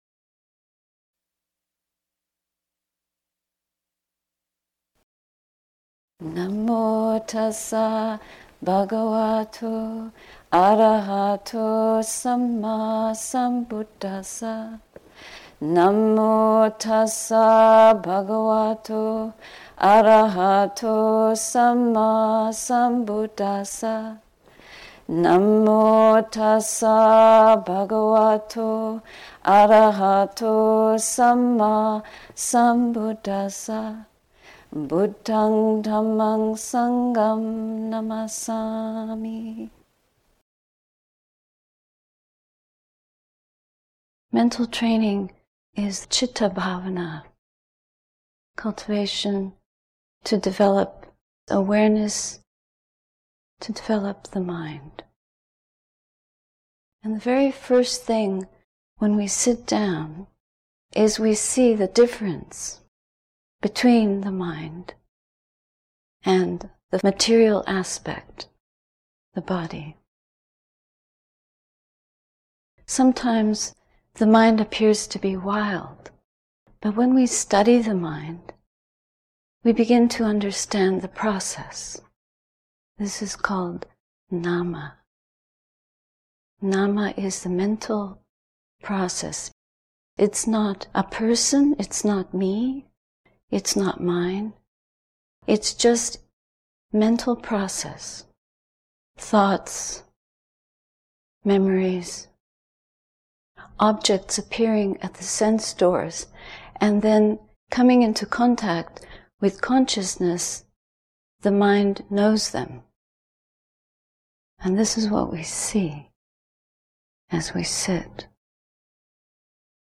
Insight Meditation Society Retreat, May 6, 2013